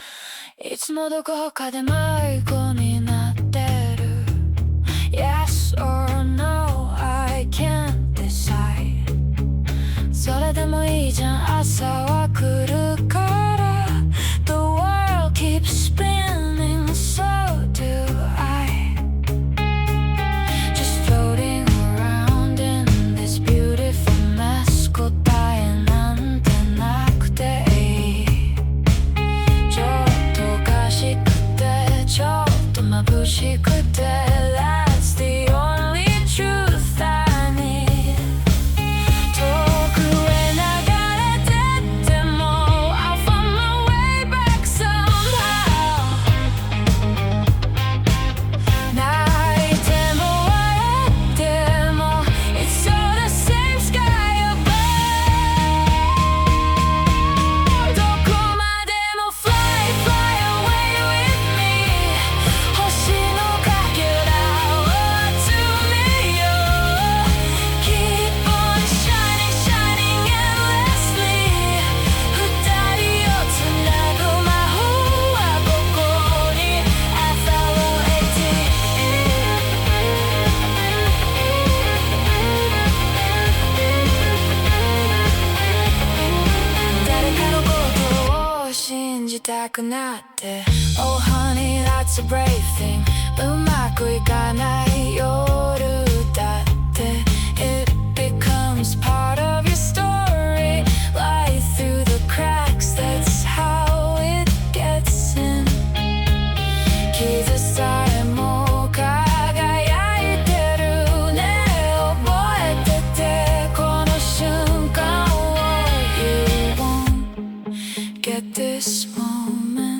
j-pop